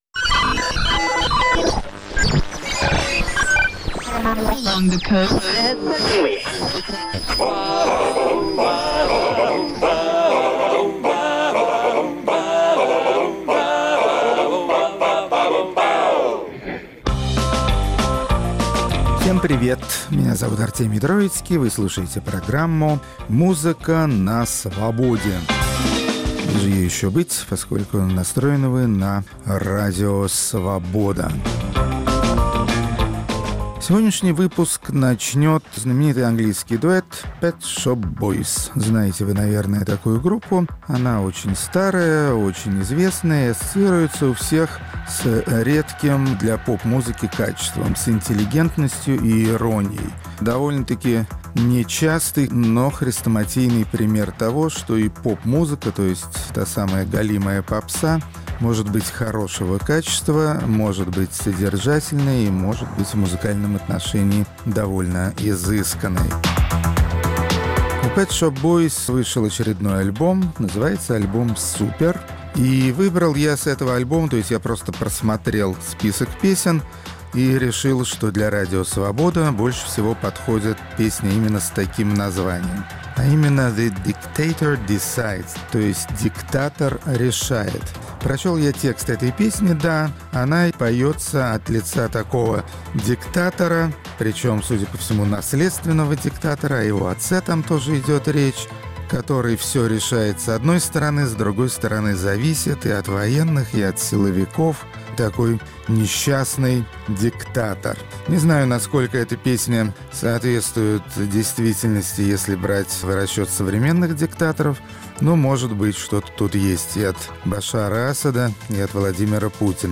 Хедлайнеры двадцать третьего выпуска программы "Музыка на Свободе" – латиноамериканские певицы. Рок-критик Артемий Троицкий совершает путешествие в мир сенсуальной музыки, зажигательных ритмов и чувственного женского вокала.